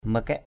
/mə-kɛʔ/ makaik m=kK [Cam M] (đg.) giận dữ = se mettre en colère = get angry.